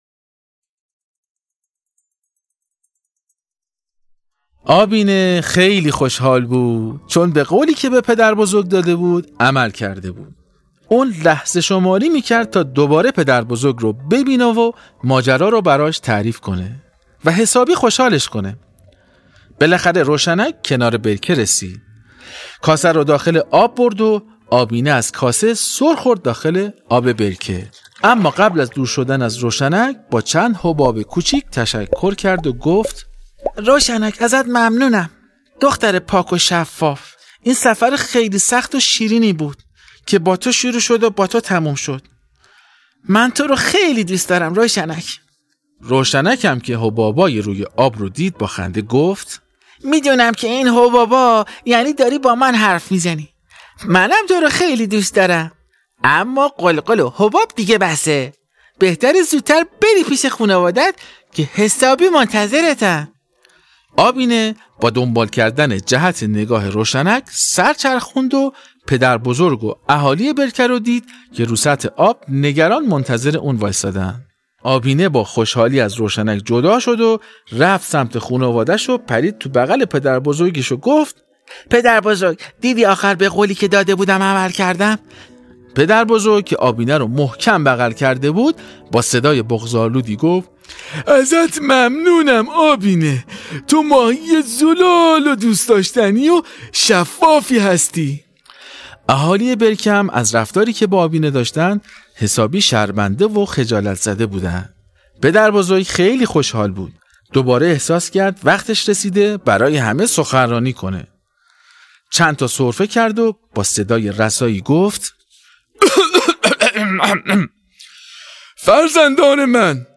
داستان ماهی ای که از یه جریان خیلی مهم برامون صحبت میکنه. باهم قسمت نهم از داستان صوتی آبینه رو بشنویم.